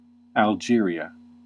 Pronunciation : / æ l ˈ dʒ ɪər i ə /
En-us-Algeria.ogg.mp3